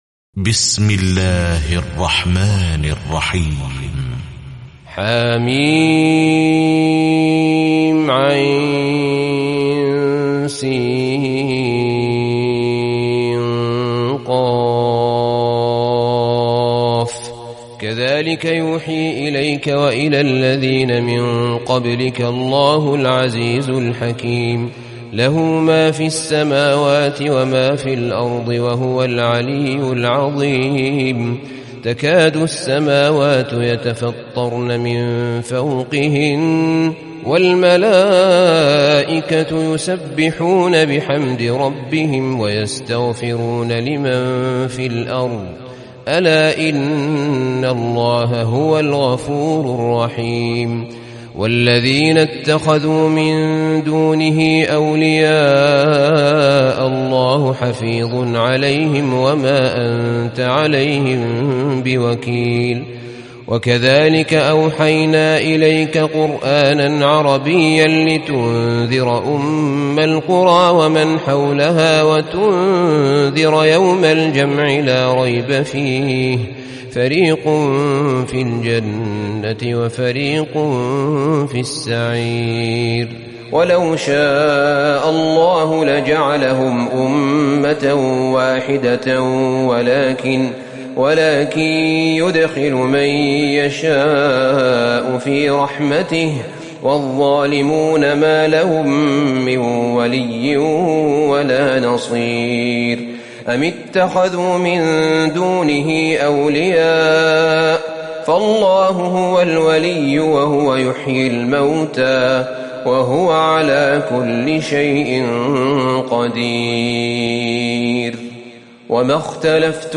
تراويح ليلة 24 رمضان 1438هـ من سورتي الشورى و الزخرف (1-25) Taraweeh 24 st night Ramadan 1438H from Surah Ash-Shura and Az-Zukhruf > تراويح الحرم النبوي عام 1438 🕌 > التراويح - تلاوات الحرمين